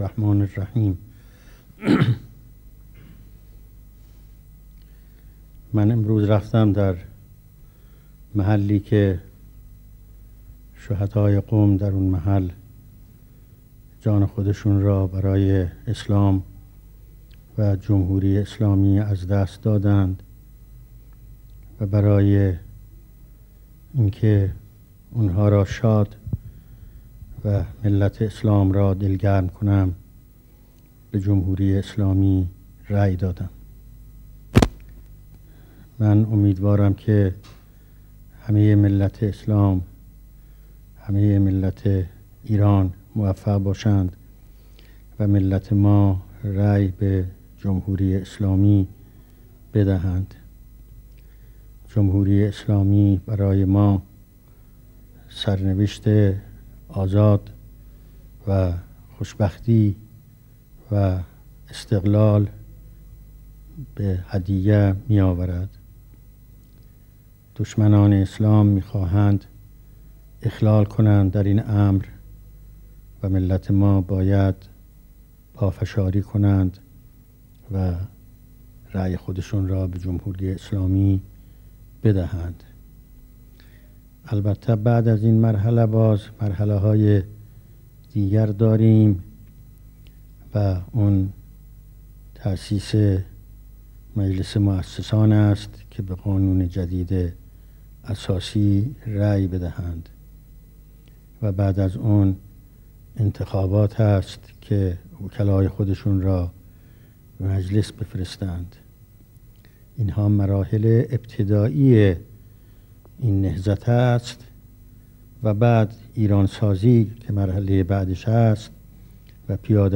سخنرانی در جمع مردم و تاکید بر رای به «جمهوری اسلامی»